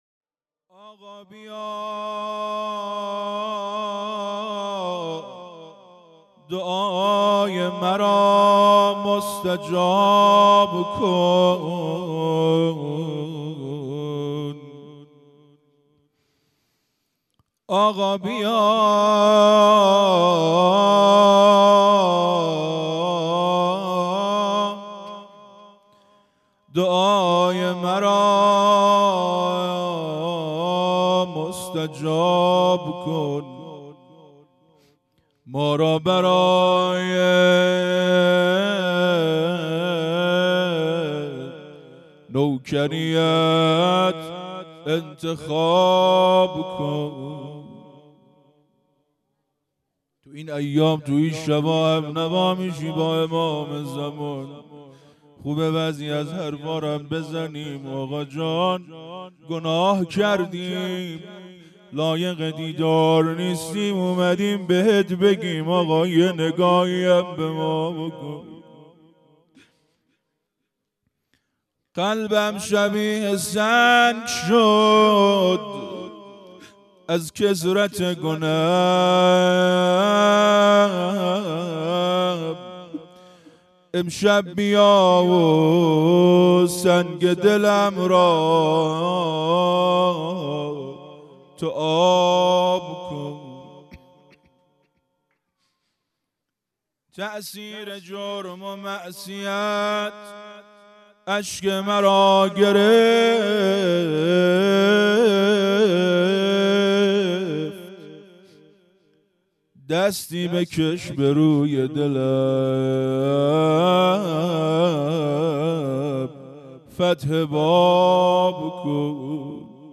روضه | آقا بیا دعای مرا مستجاب کن مداح
فاطمیه اول _ شب اول